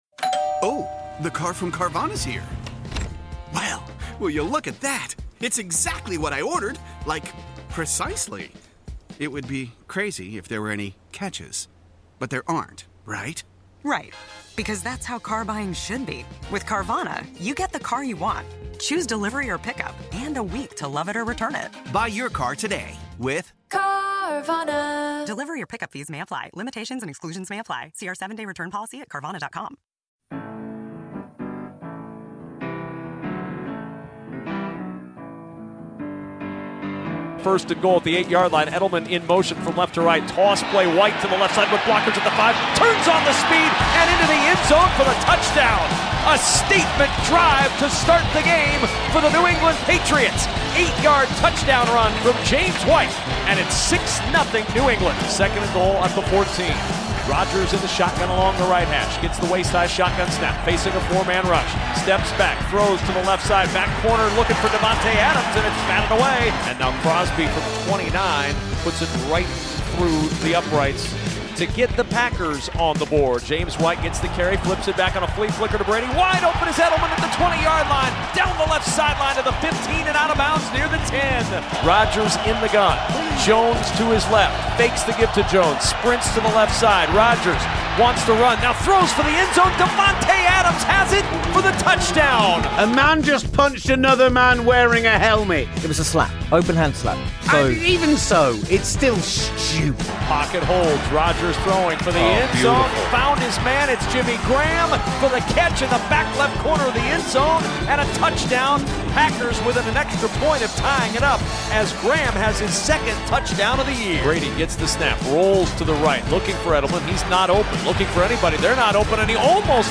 TGS 242 - Live from a Michigan Car Park, plus Jason McCourty & Stefon Gilmore